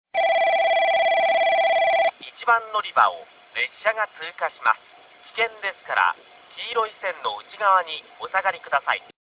1番のりば通過放送　男声   かつては、JACROS簡易型でした。この頃から音量が「超激小」でした。
また実際の放送は2回流れ、ベルは収録機器の都合で頭切れしています。